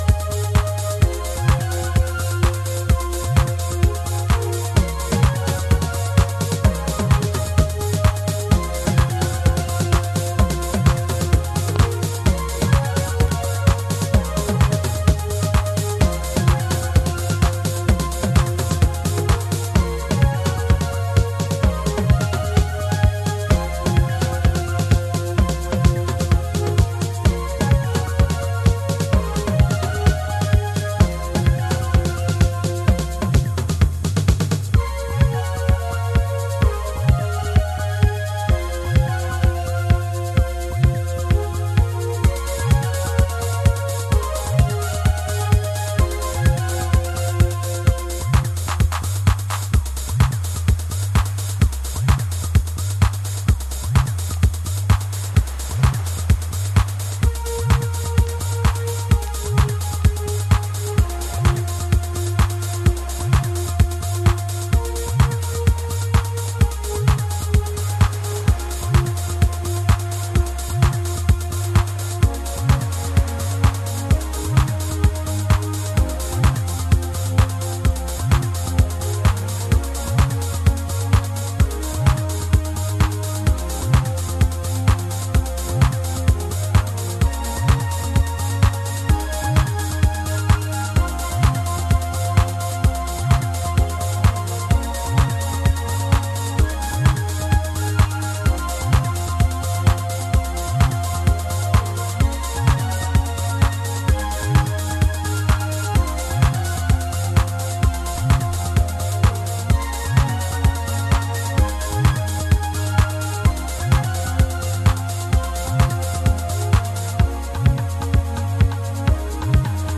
あらゆるヴィンテージ機材を駆使して、80年代後期のシカゴハウスにアプローチ。